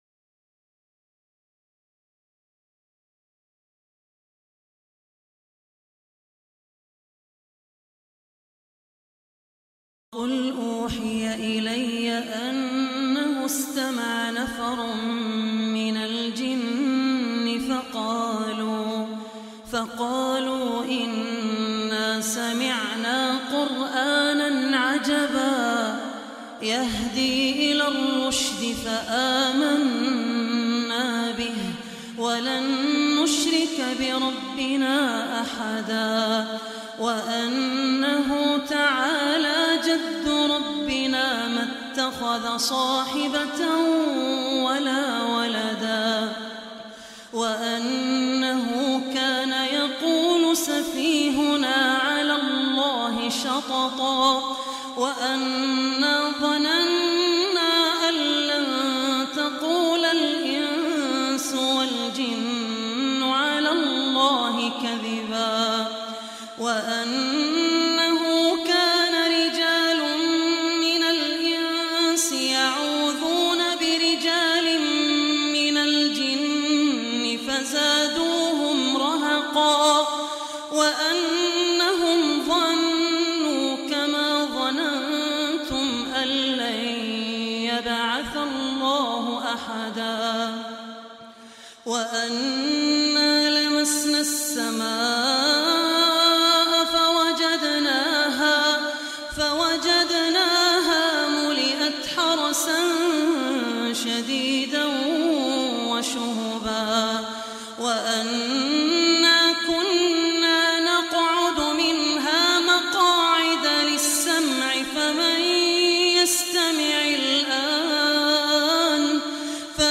Surah Jinn Recitation by Abdur Rahman Al Ossi
Listen online and download beautiful Quran Recitation / Tilawat of Surah Al Jinn in the heart touching voice of Sheikh Abdur Rehman Al Ossi.